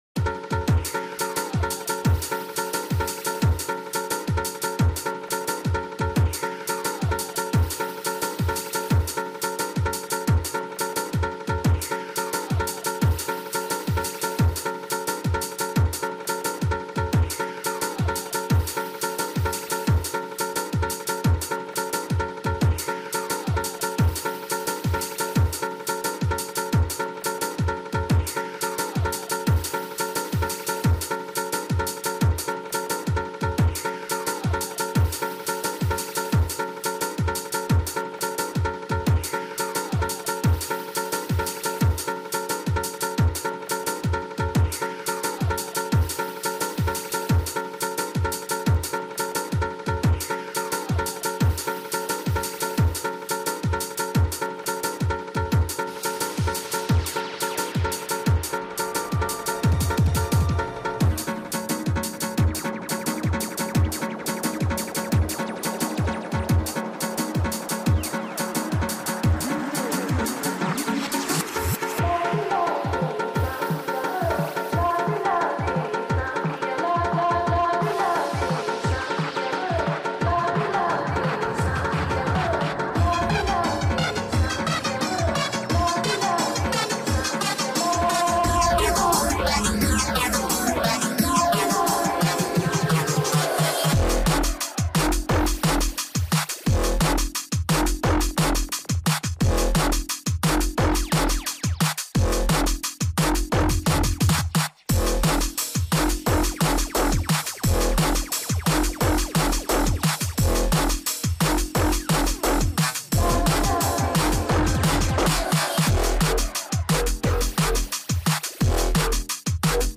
A bit of jump up madness.
Drum & Bass Hardstyle Bounce